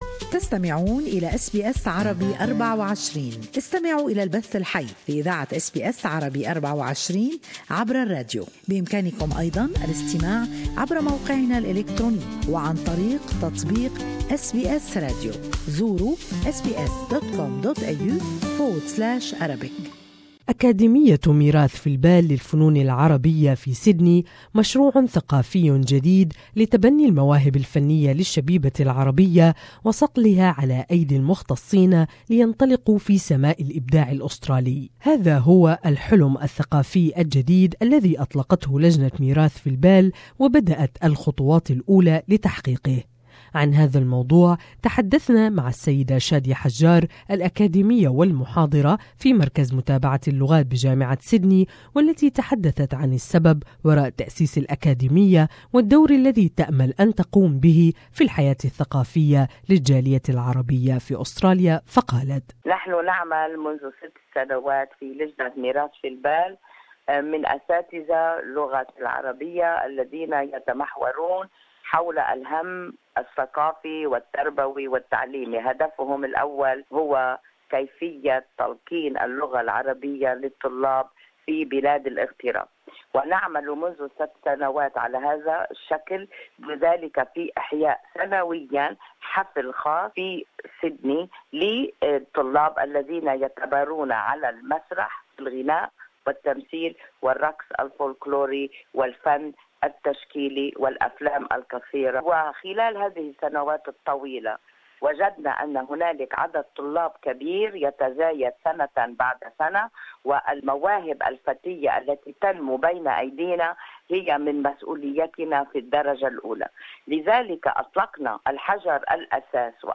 Mirathin Mind has launched a new Academy for arts and culture in order to foster and support young talents more in this interview